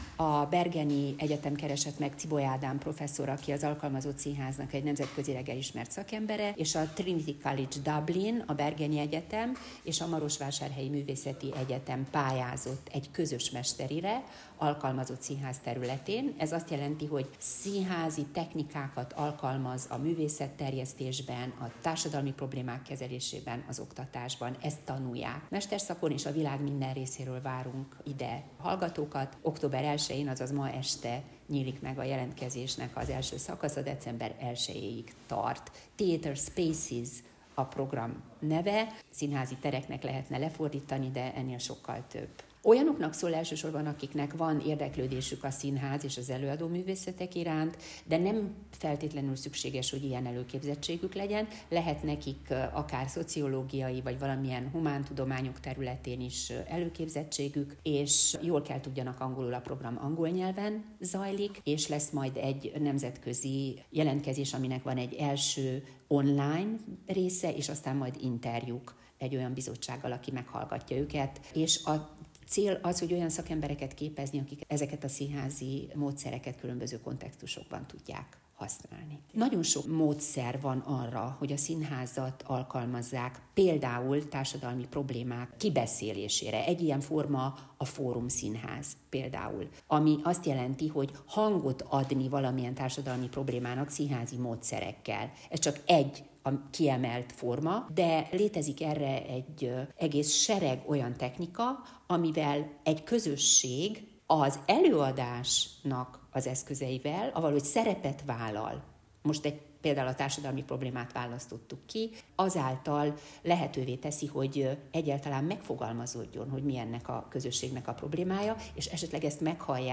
Október 1-jén sajtótájékoztatón ismertette új tanévi programját a Marosvásárhelyi Művészeti Egyetem vezetősége.